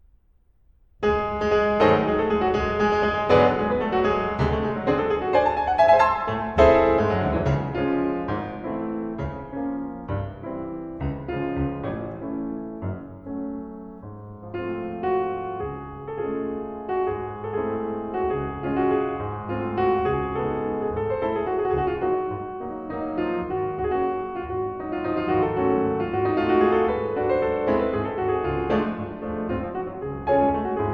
Musique audio